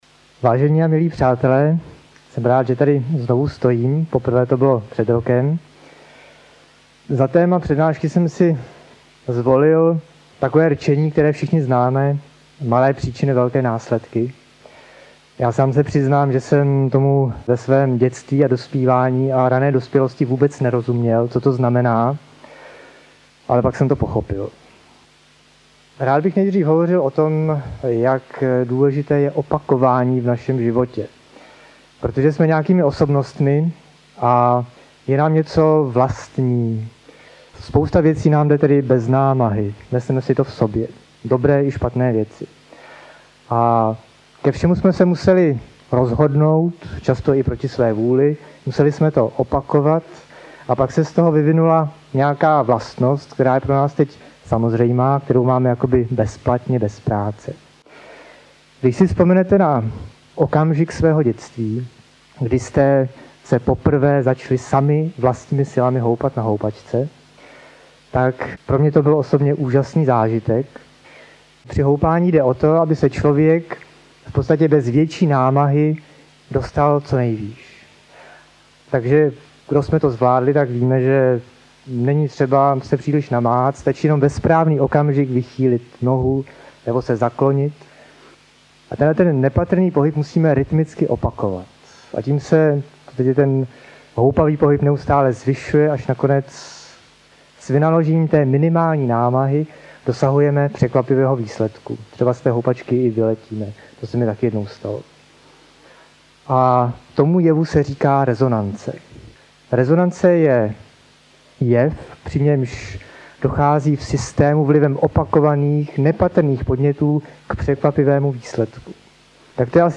AudioKniha ke stažení, 1 x mp3, délka 42 min., velikost 76,6 MB, česky